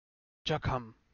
d͡ʒ
ಜಖಂ jakhaṁ 'wound' badger